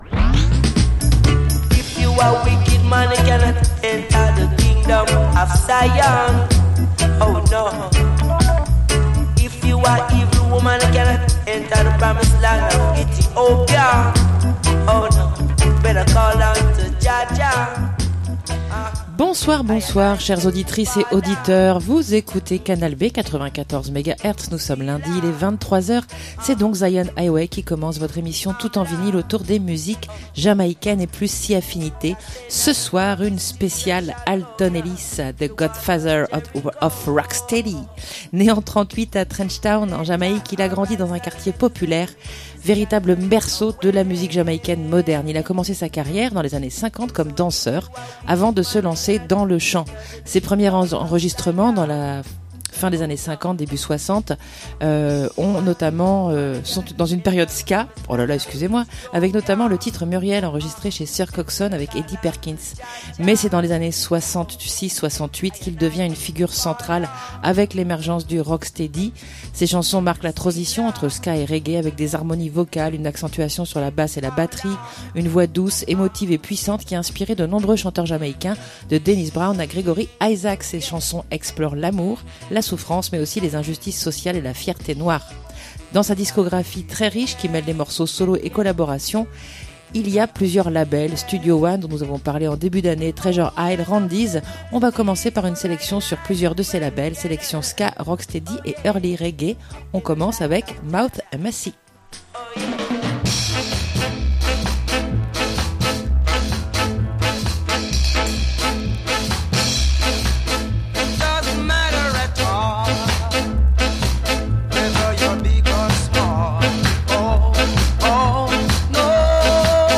le crooner .
ska, rockteady et early reggae